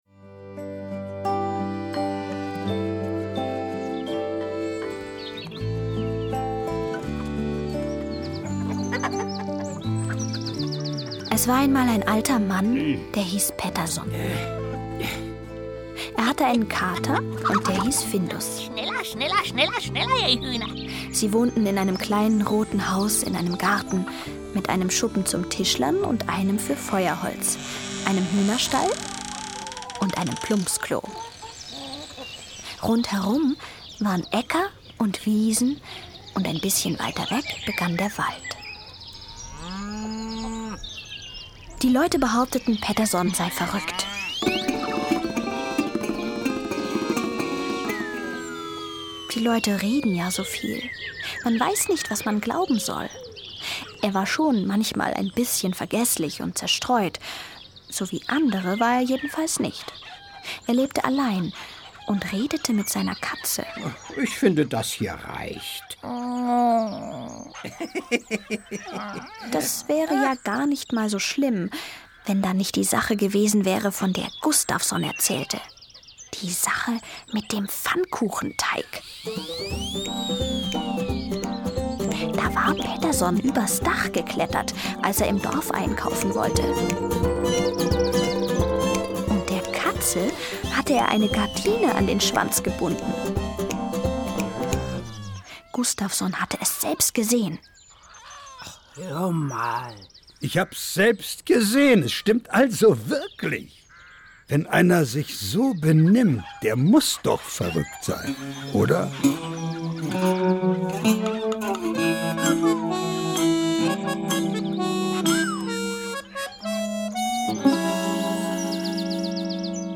Hörbuch: Pettersson und Findus.